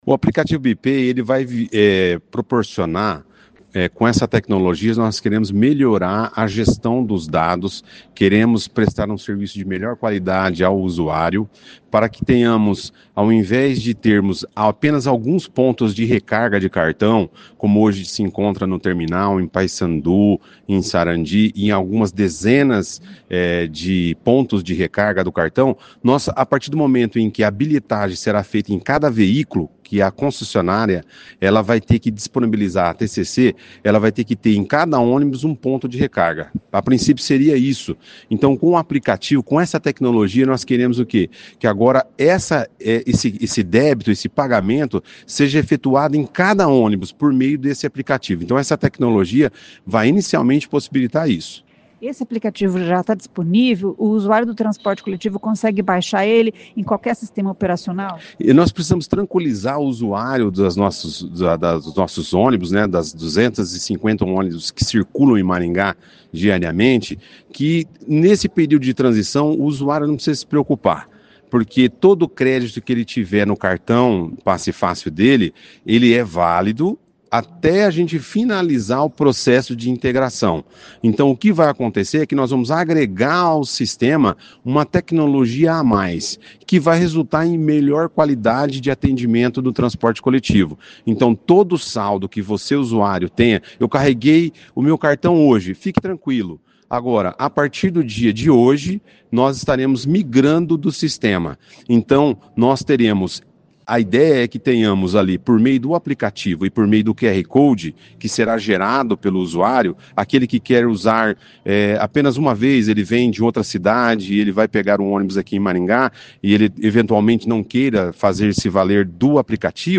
Ouça o que diz o secretário Luciano Brito: